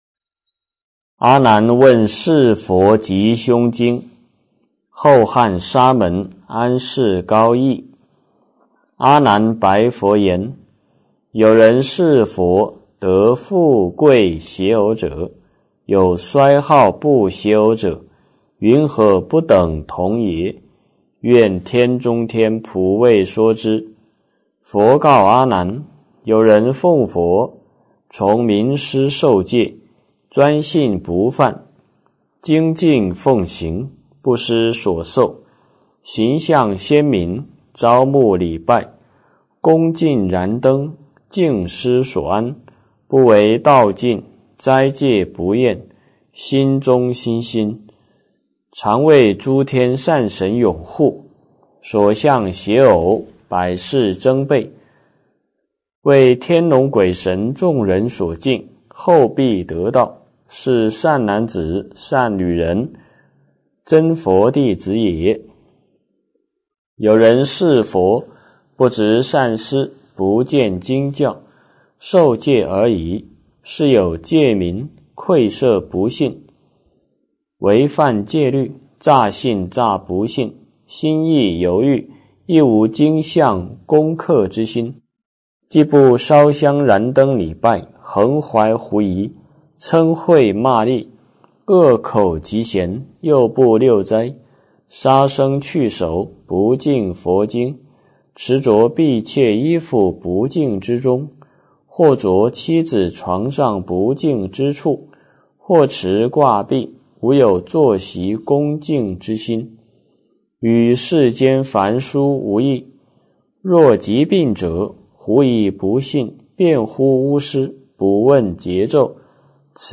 阿难问佛吉凶经 - 诵经 - 云佛论坛